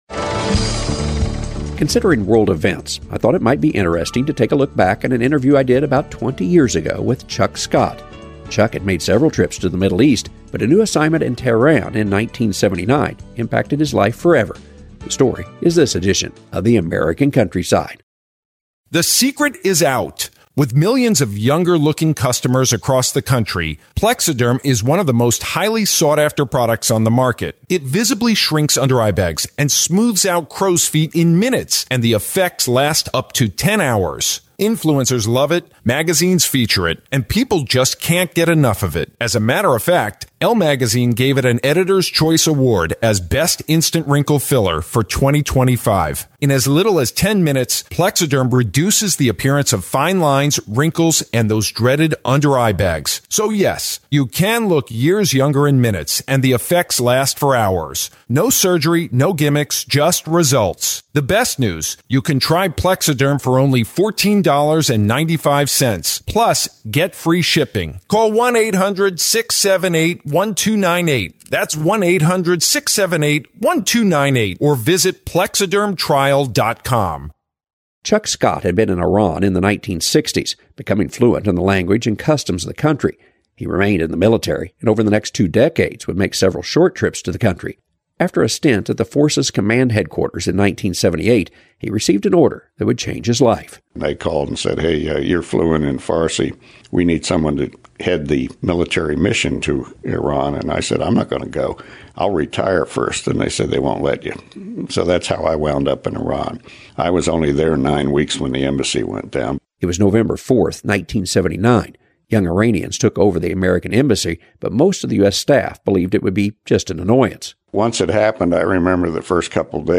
Considering world events, I thought it might be interesting to take a look back at an interview